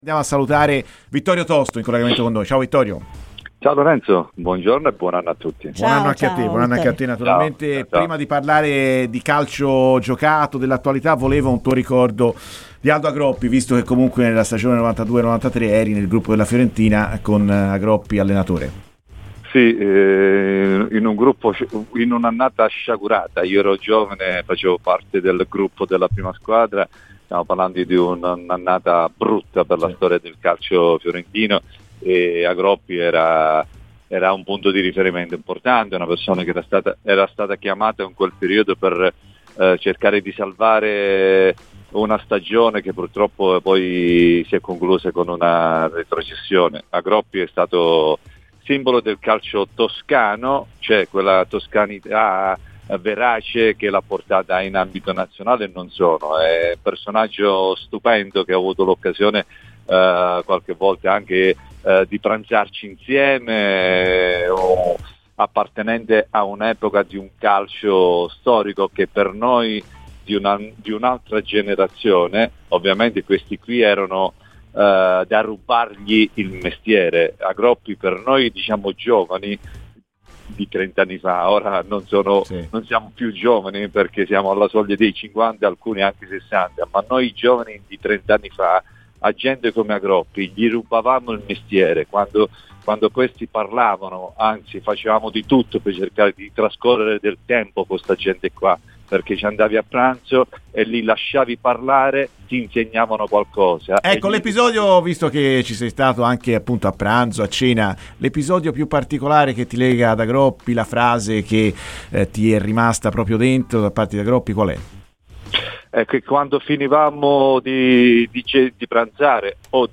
è intervenuto a Radio FirenzeViola durante la trasmissione "Viola amore mio"